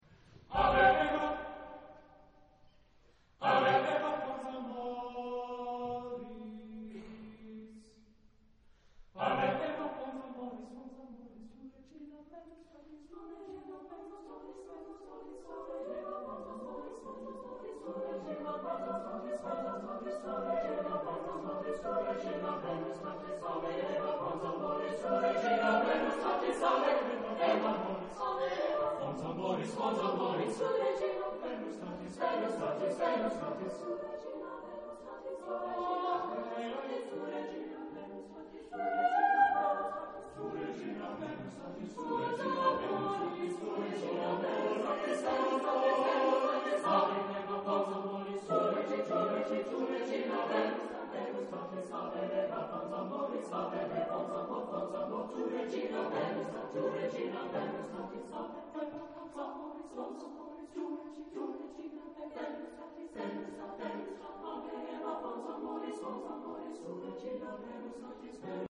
Epoque : 20ème s.
Genre-Style-Forme : Etude
Caractère de la pièce : énergique ; turbulent
Type de choeur : SSAATTBB  (8 voix mixtes )
Tonalité : do (centré autour de)